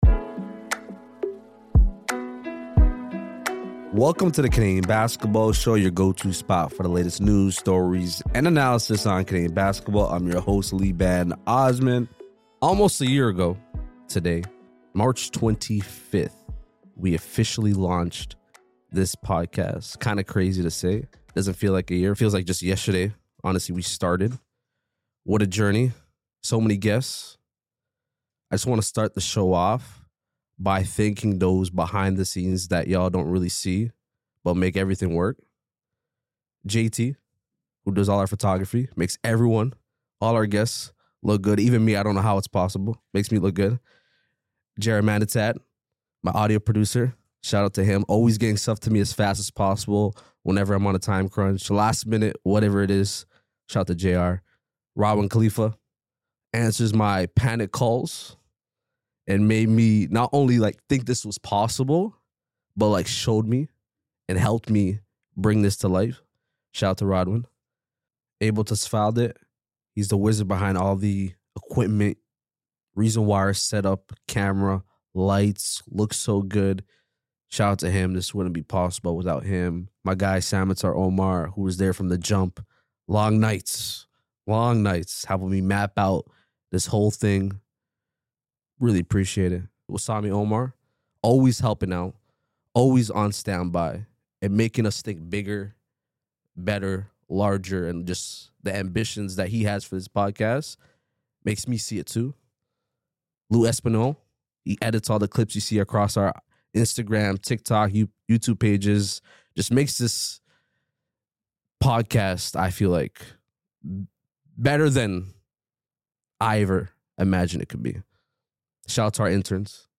After calling the U Sports Final 8 tournament, national sports broadcast Arash Madani joins the show to discuss what changes need to be made and the state of university basketball in Canada.